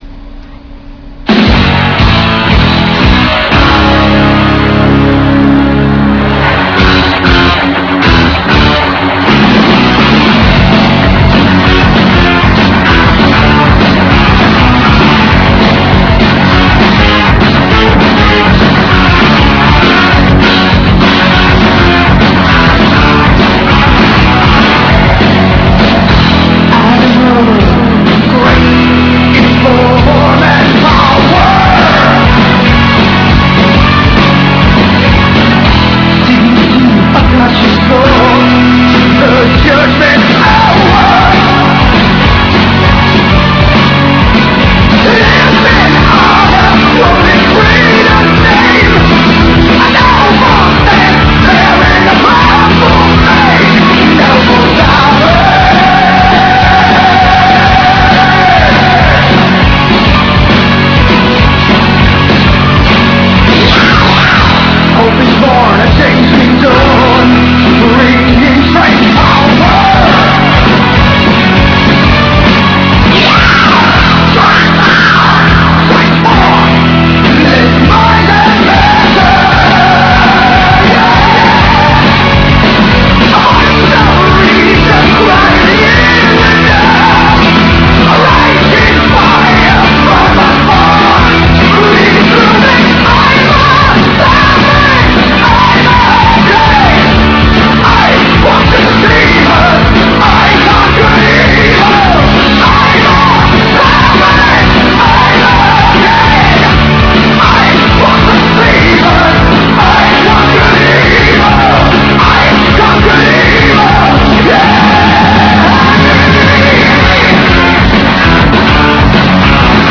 with strong guiter playing
metal classic